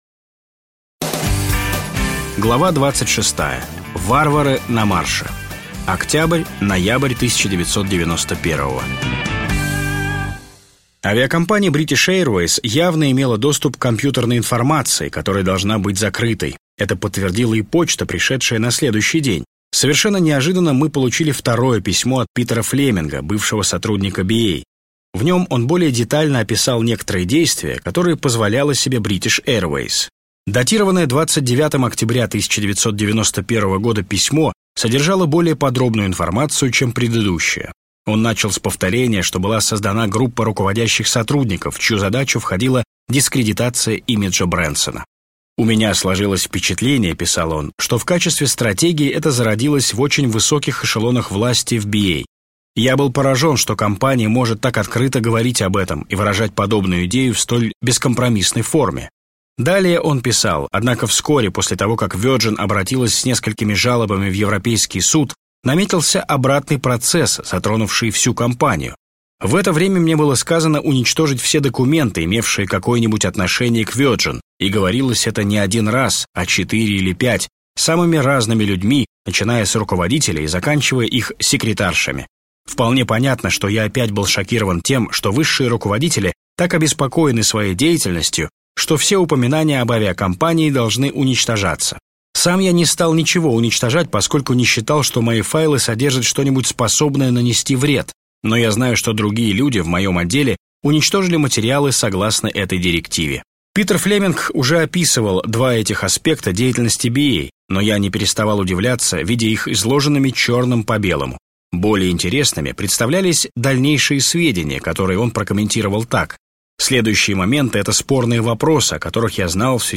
Аудиокнига Теряя невинность | Библиотека аудиокниг